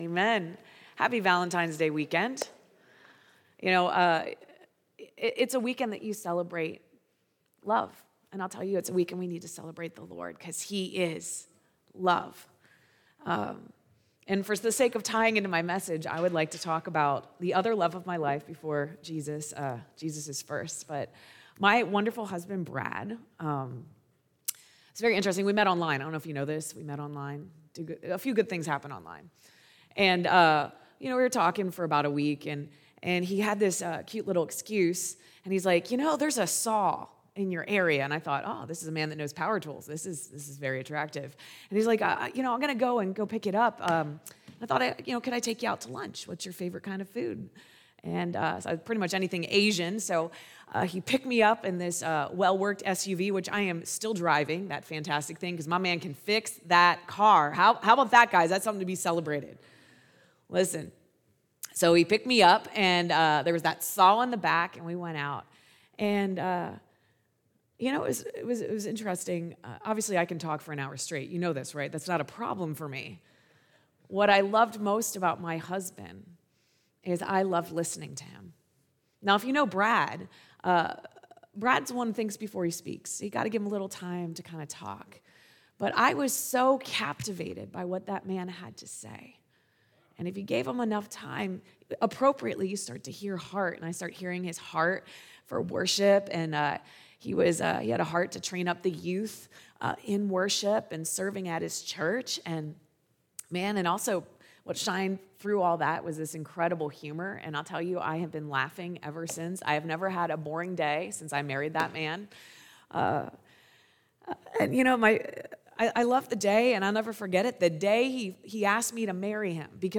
Sunday AM Service